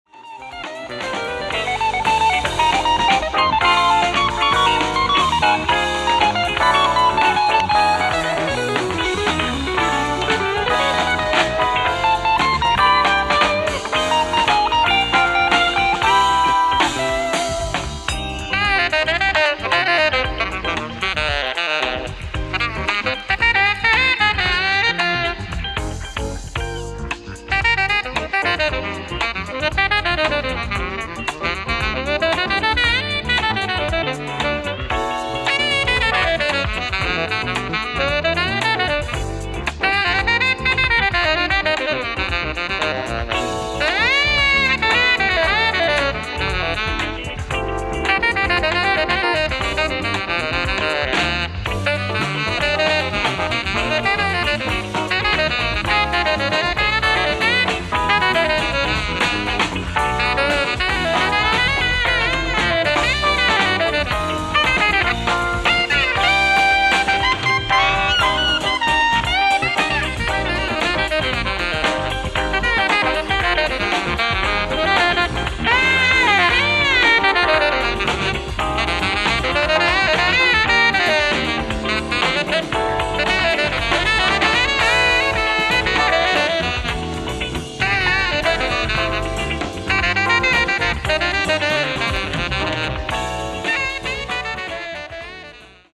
ライブ・アット・ハマスミスオデオン、ロンドン 1980
ノイズレスのレストア済音源！！
※試聴用に実際より音質を落としています。